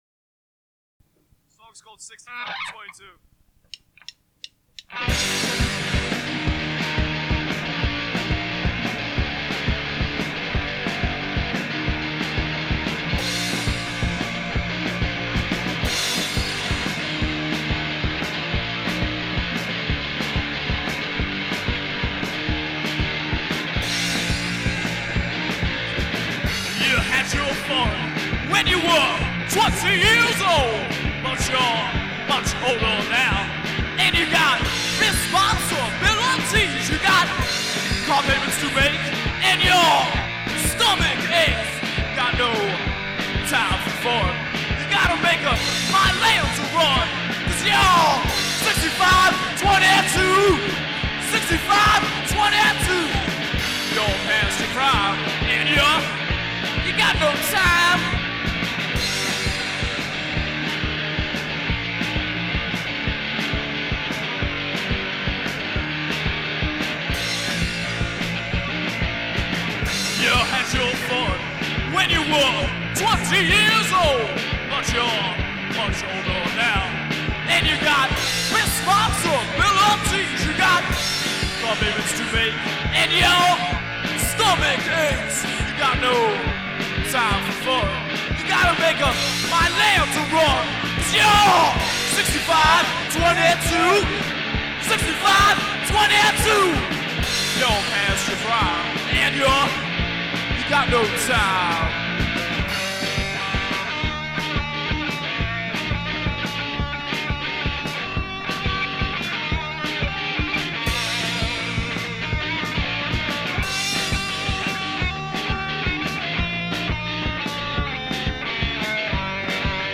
Live on WKDU 1/23/90